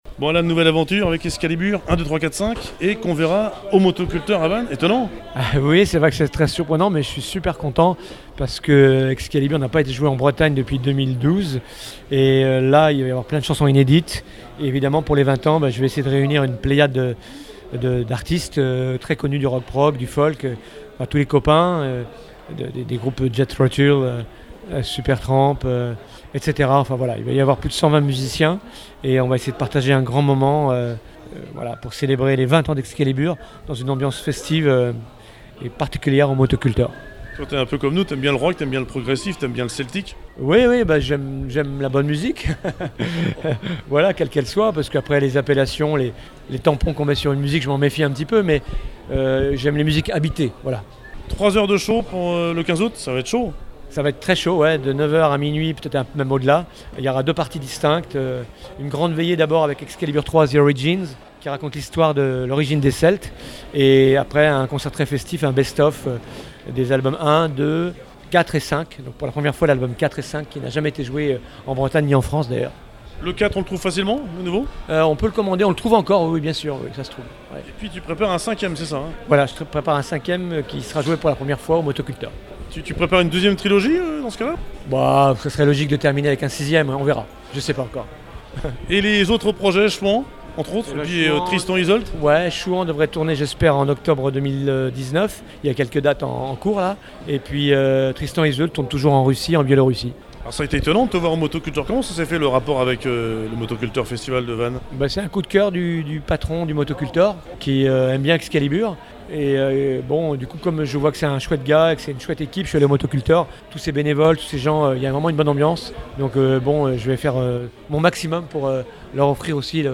Alan Simon créateur d’Excalibur présente le concert au MotoCultor Festival 2019